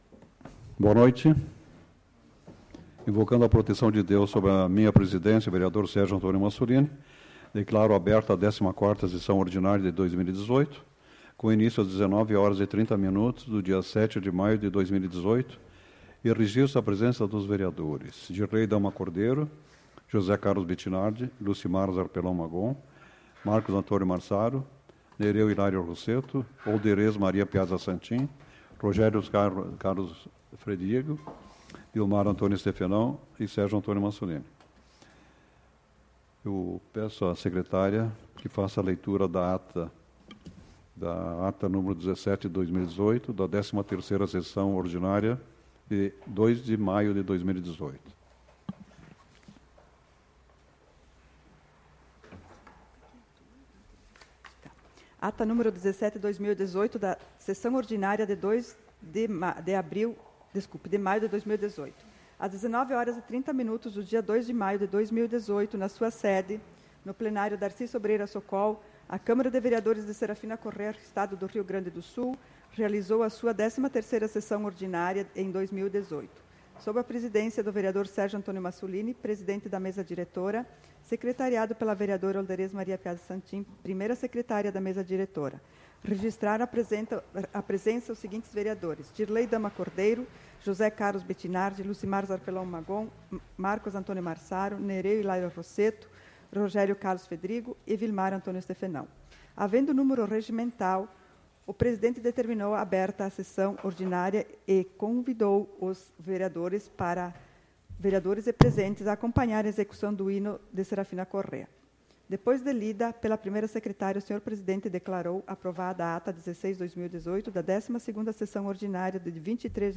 SAPL - Câmara de Vereadores de Serafina Corrêa - RS